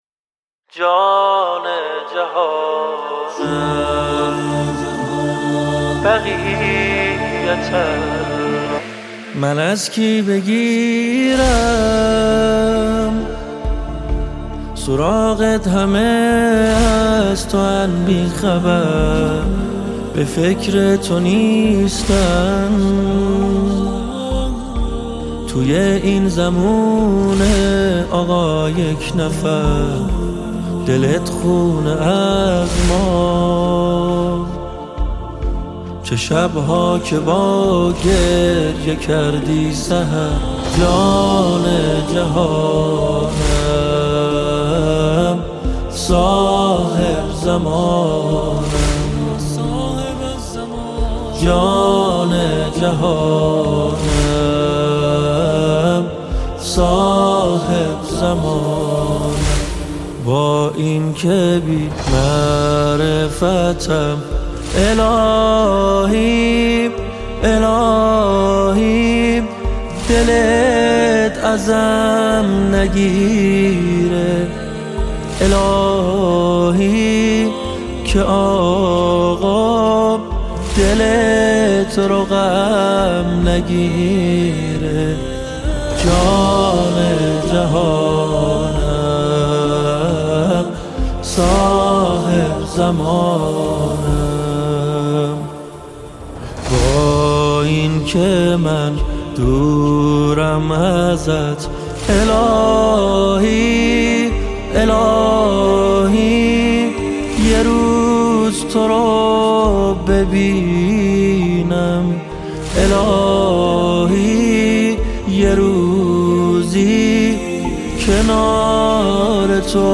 نماهنگ و مناجات مهدوی